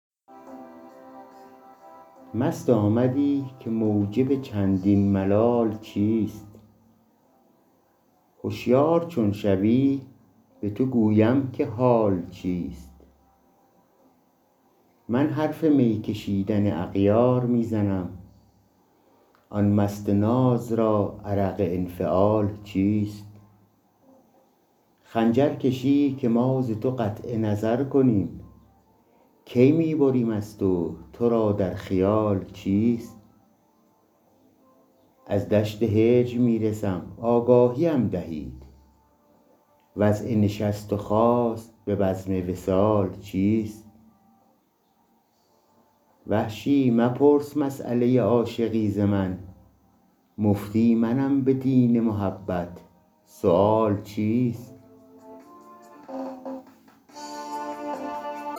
وحشی بافقی دیوان اشعار » غزلیات شمارهٔ ۷۹ به خوانش